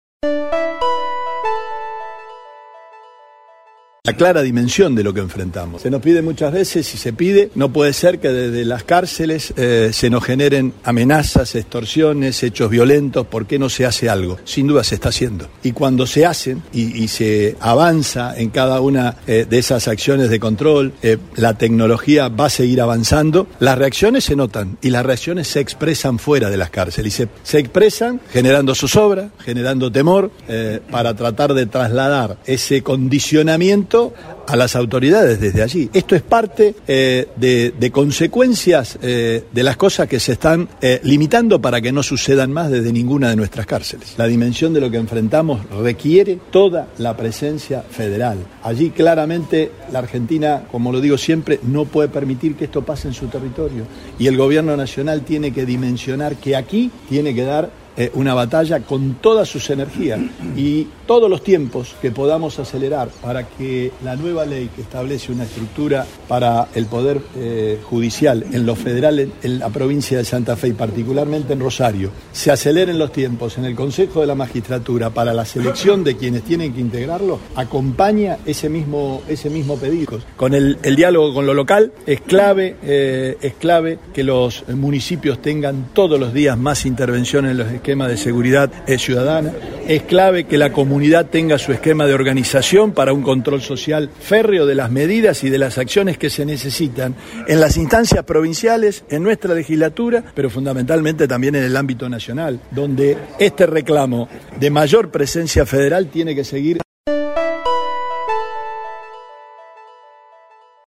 En contacto con la prensa el mandatario santafesino hizo referencia a las balaceras en las escuelas de la ciudad. Afirmó que muchos de los hechos violentos son consecuencia del avance en los controles dentro de las cárceles y son una reacción.